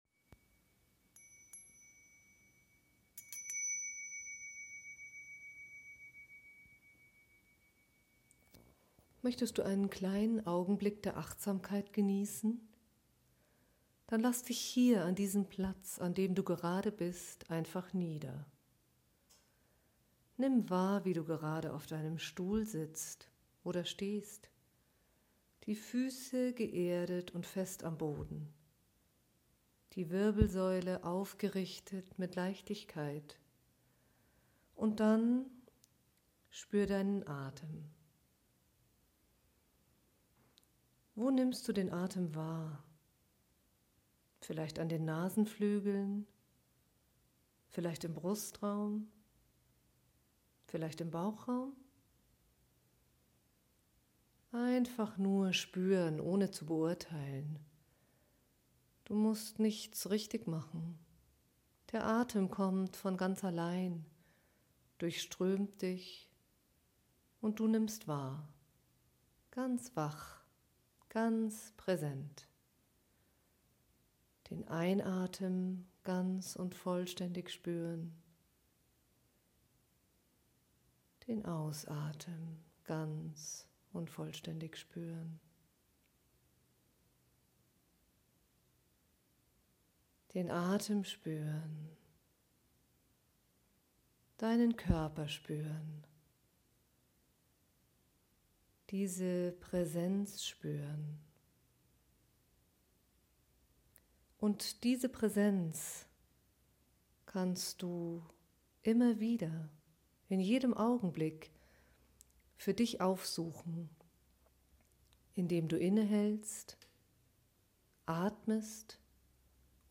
Einladung zur Meditation
Für die täglichen Übungen zu Hause, die das Erlernte im Alltag verankern, habe ich Audiodateien aufgenommen, die den Einstieg ins Meditieren erleichtern.